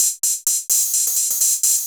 Index of /musicradar/ultimate-hihat-samples/128bpm
UHH_ElectroHatC_128-05.wav